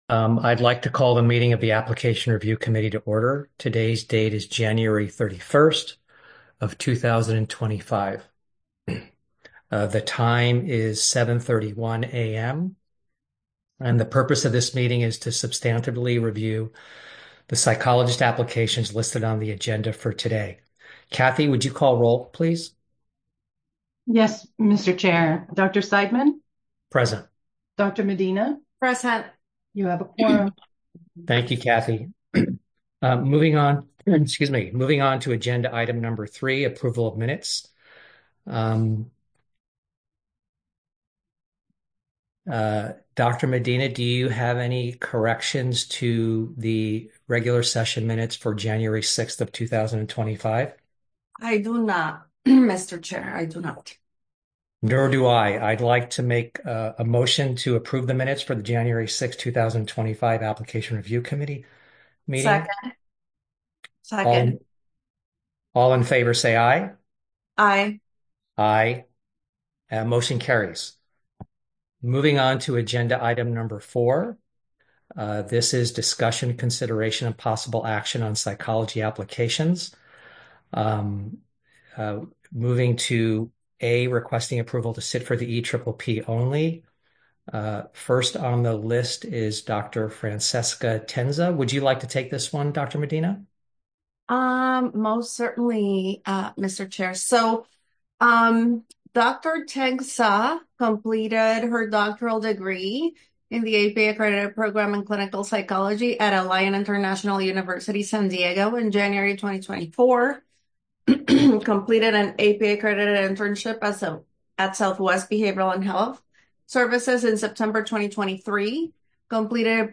Application Review Committee Meeting | Board of Psychologist Examiners
The meeting is being held virtually via Zoom.